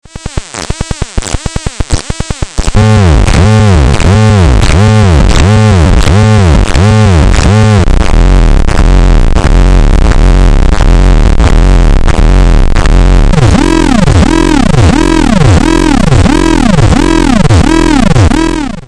这个绿不拉几的玩音是个八度类的单块，用ZAKK的话说，它拥有放屁一样的声音！在4MS里它们叫噪音类效果器（也确实很噪音）